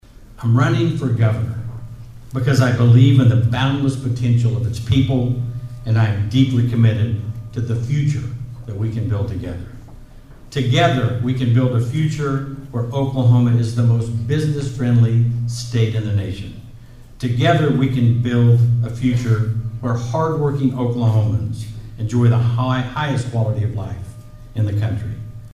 During his announcement Monday, Drummond said he is not running for governor to earn another elected title.